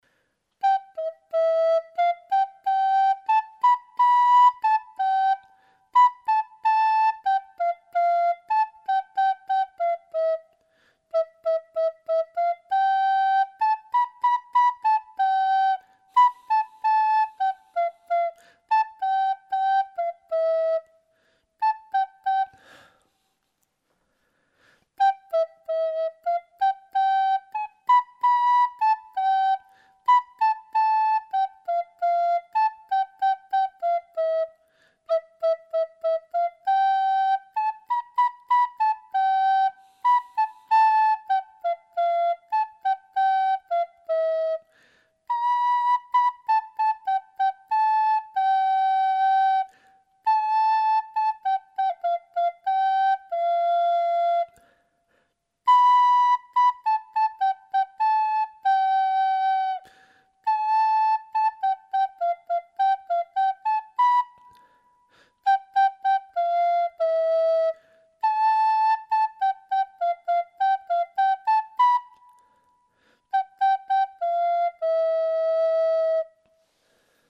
Outra melodía coas notas MI, FA, SOL, LA e SI.